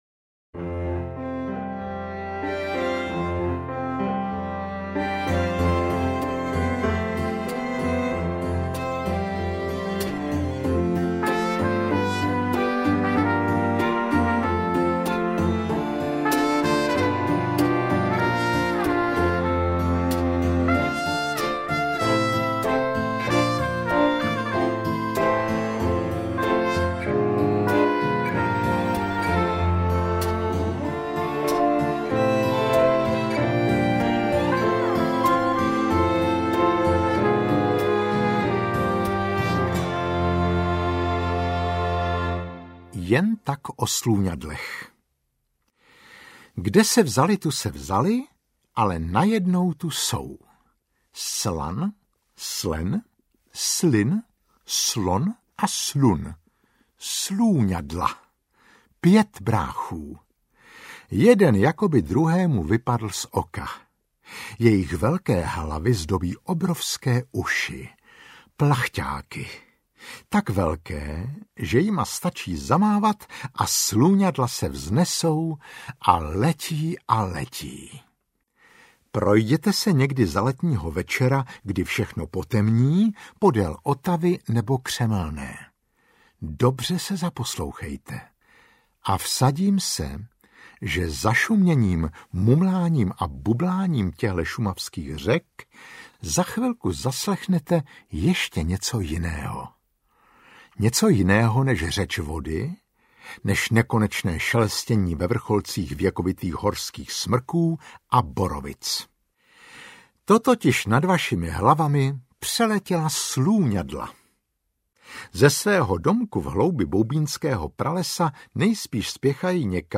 Interpret:  Marek Eben
AudioKniha ke stažení, 10 x mp3, délka 3 hod. 7 min., velikost 170,8 MB, česky